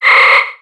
Cri de Kirlia dans Pokémon X et Y.